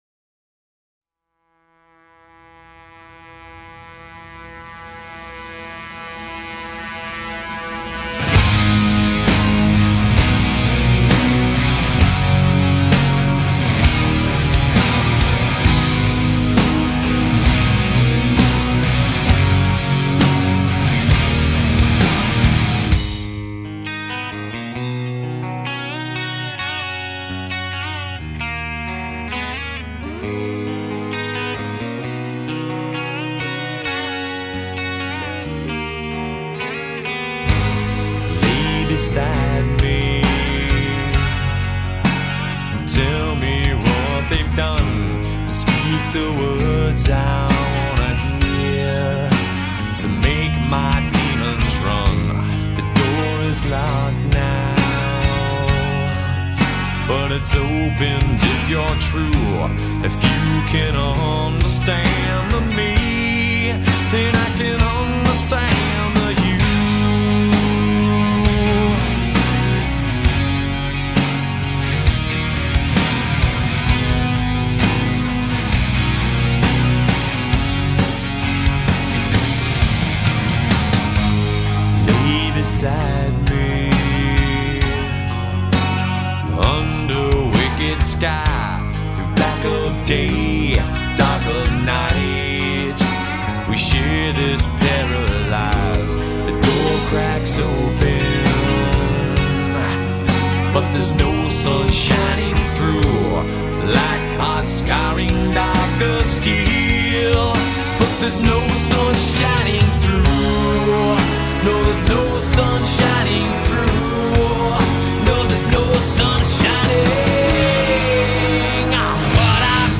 исполняющая музыку в стилях трэш-метал и хэви-метал